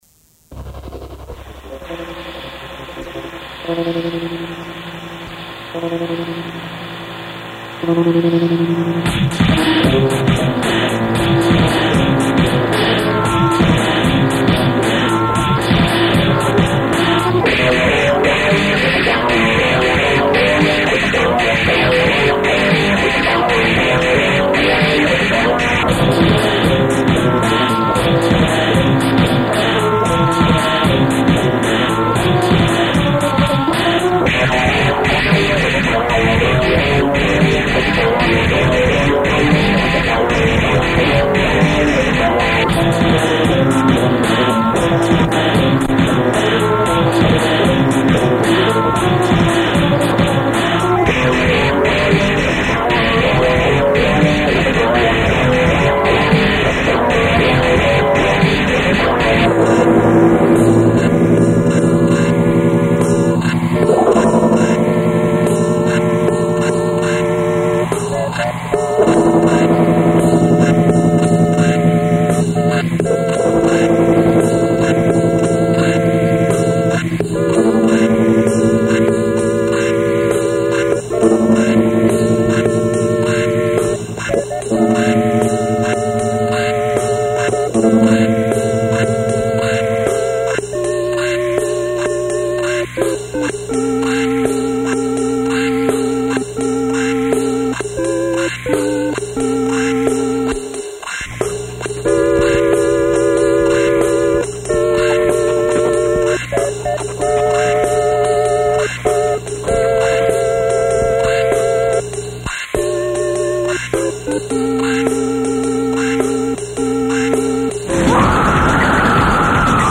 recorded by the band at Studios 509